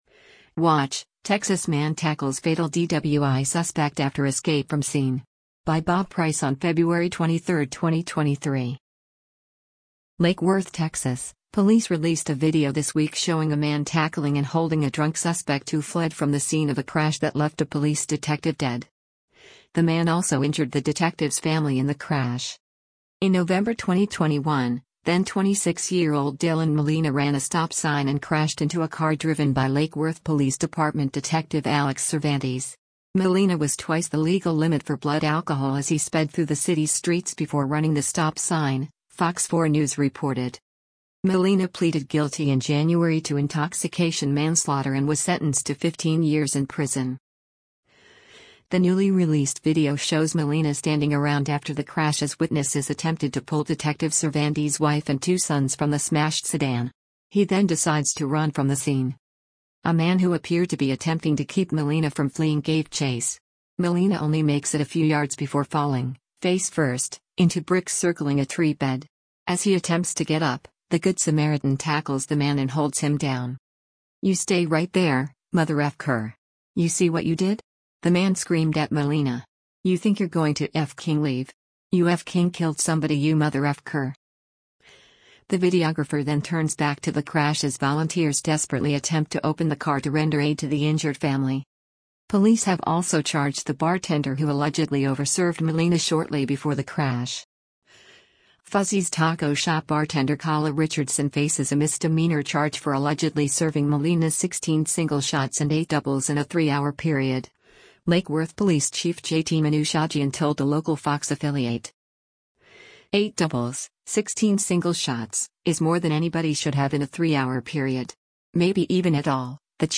The videographer then turns back to the crash as volunteers desperately attempt to open the car to render aid to the injured family.